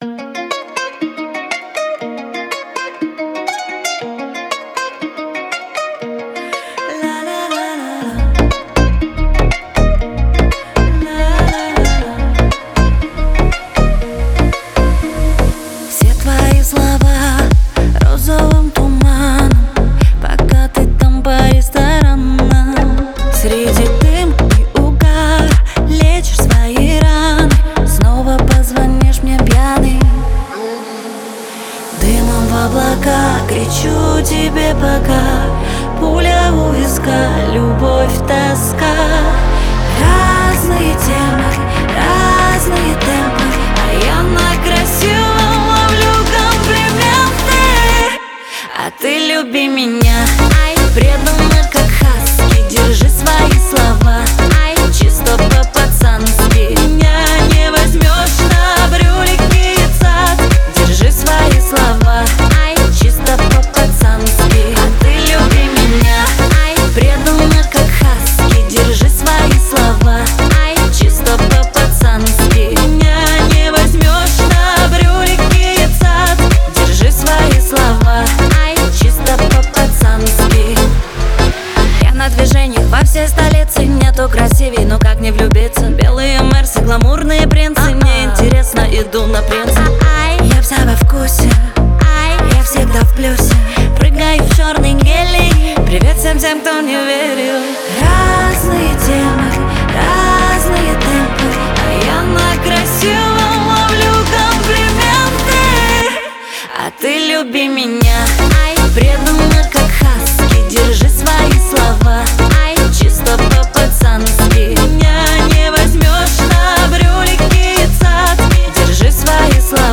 Дип хаус